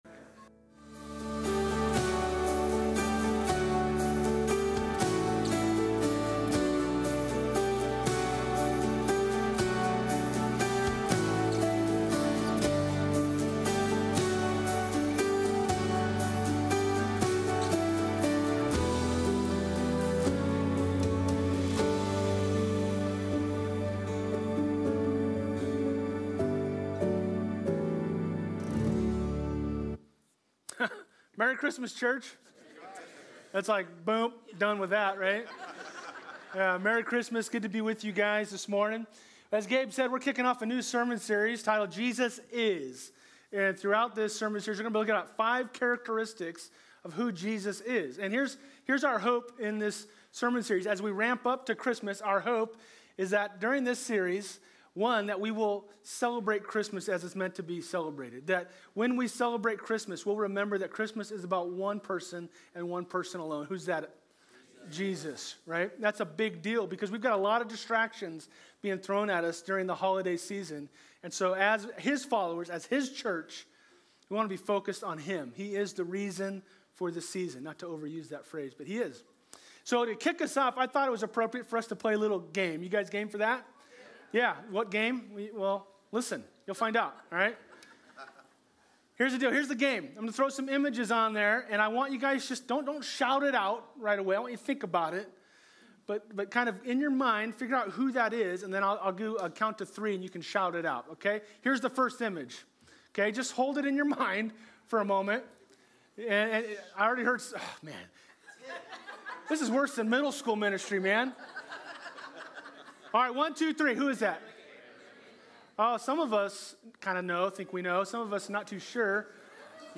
Full+Service+12.8.24+audio.mp3